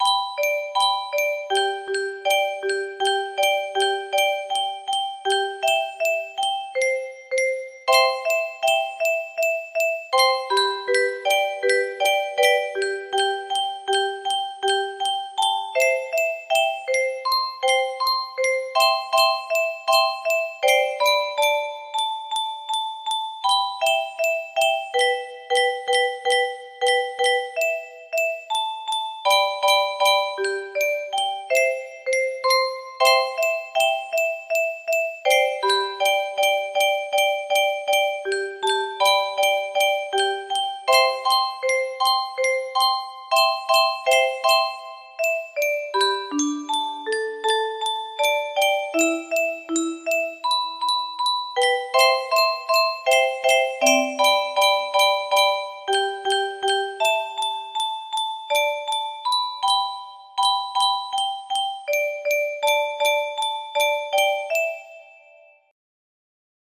Unknown Artist - Untitled music box melody
Imported from MIDI from imported midi file (14).mid